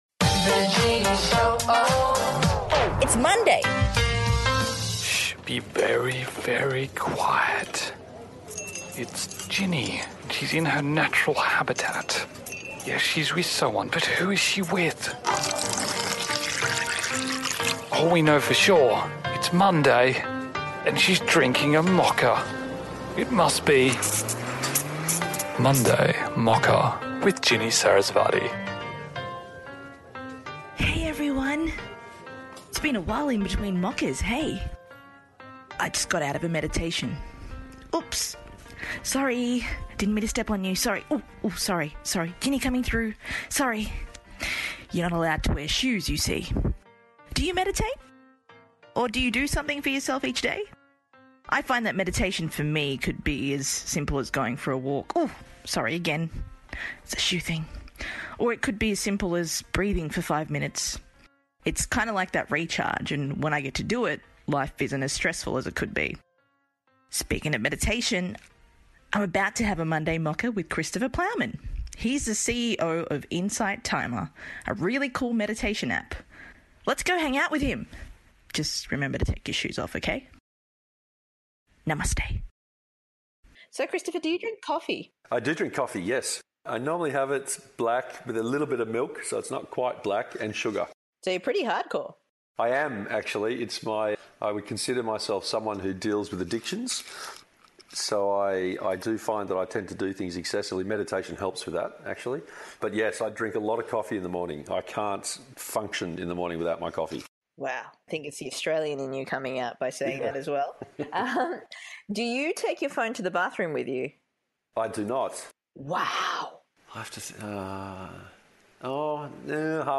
Monday Mocha is an opportunity I have to sit down and have a coffee with an awesome individual who is doing something inspiring in the world.